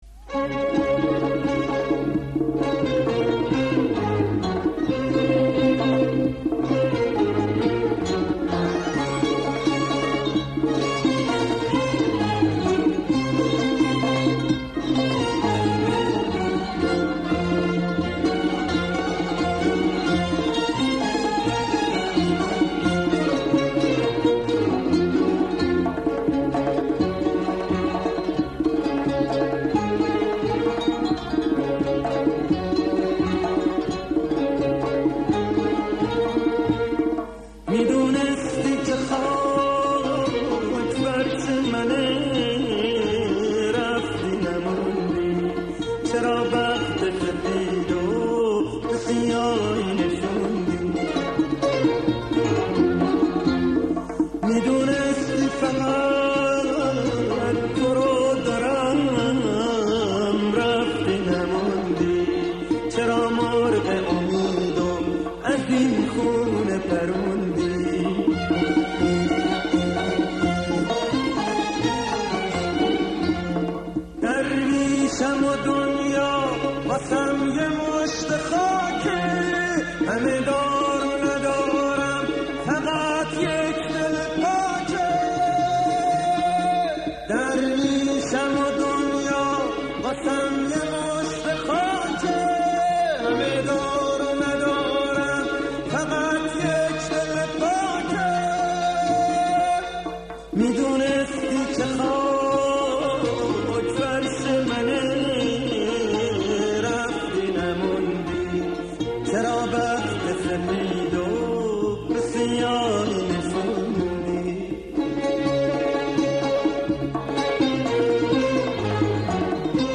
ژانر: پاپ
✅ آثار عاشقانه و احساسی با صدای گرم و پرقدرت
✅ تصنیف‌ها و آوازهایی با تنظیم‌های کلاسیک و شنیدنی